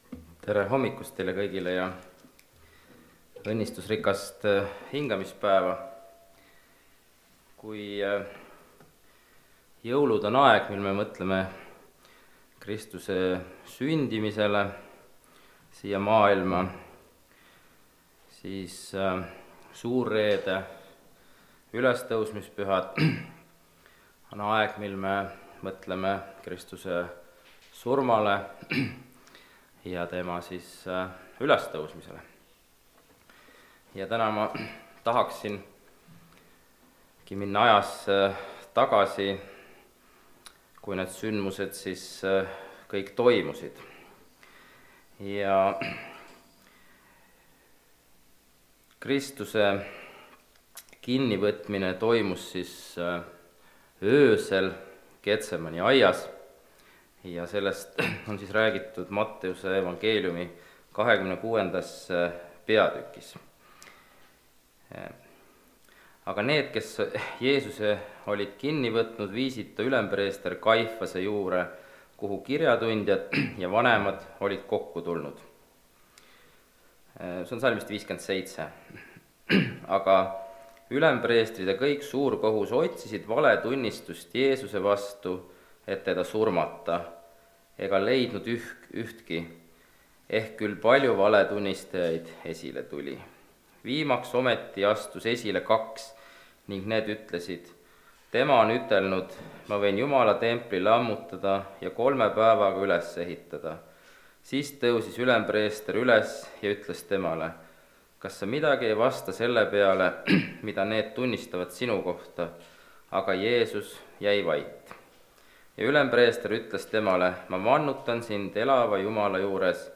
(Rakveres)
Jutlused